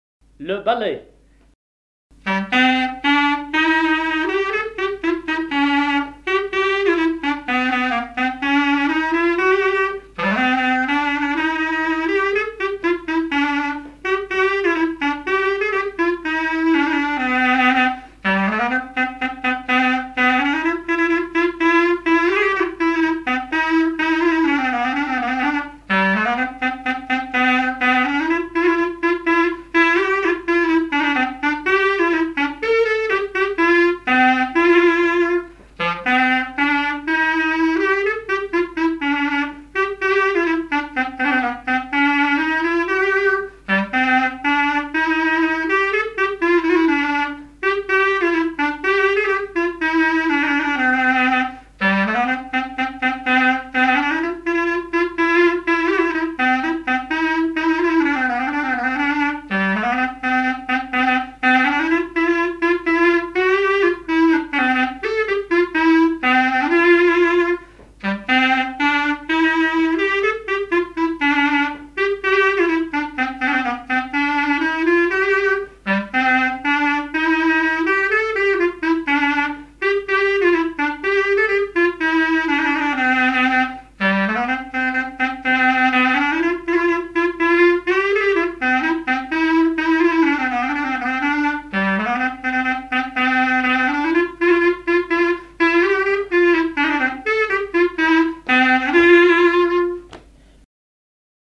danse du balais
Pièce musicale inédite